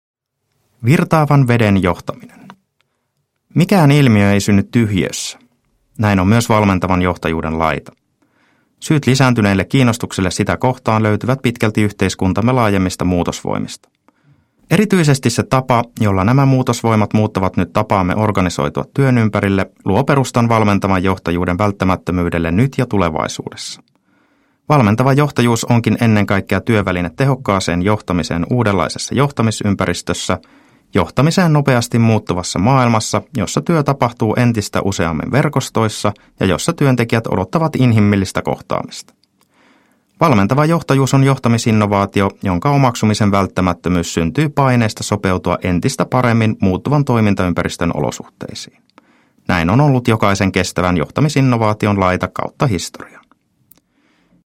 Valmentava johtajuus – Ljudbok – Laddas ner